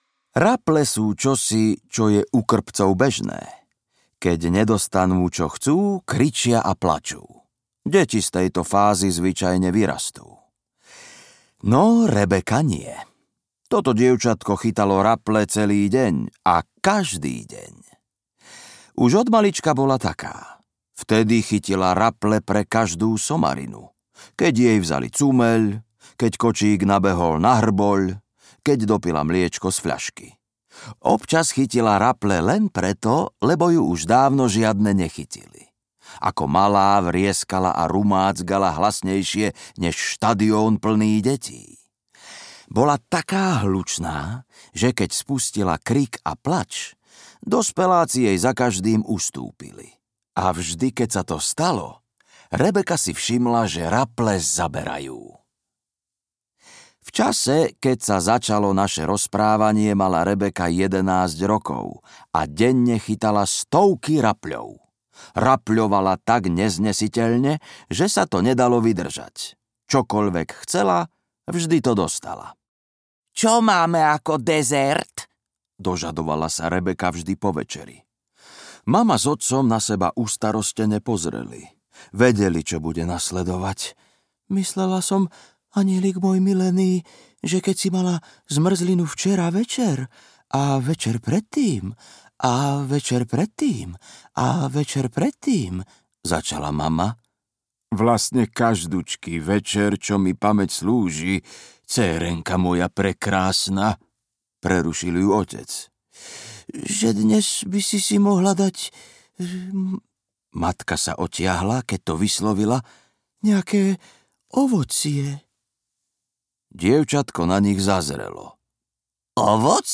Najhoršie deti na svete 3 audiokniha
Ukázka z knihy